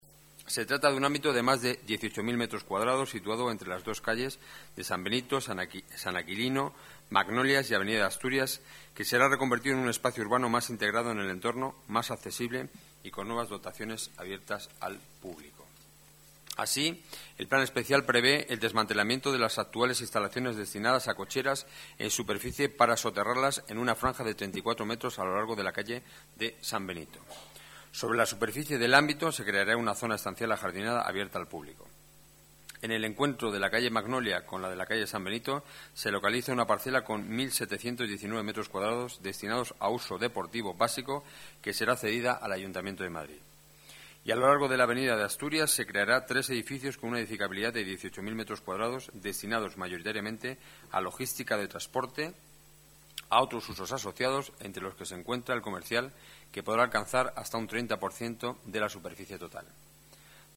Nueva ventana:Declaraciones de Enrique Núñez, portavoz del Equipo de Gobierno